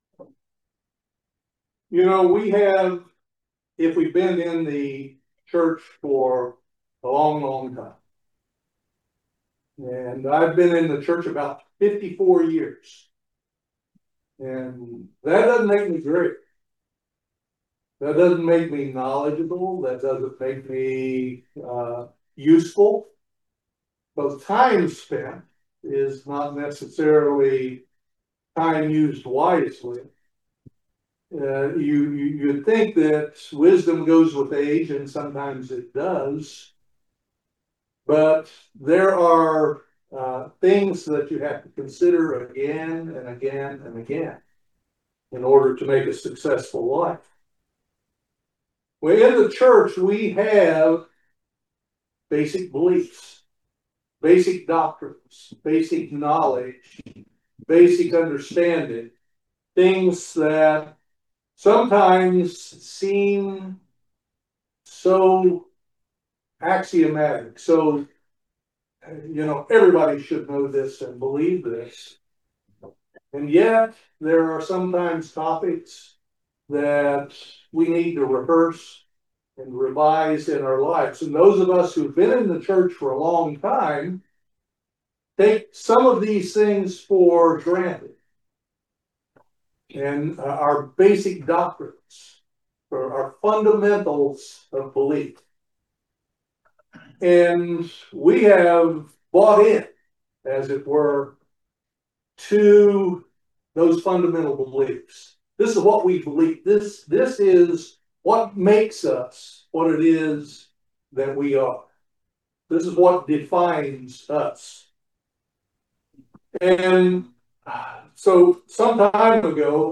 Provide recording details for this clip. Given in Lexington, KY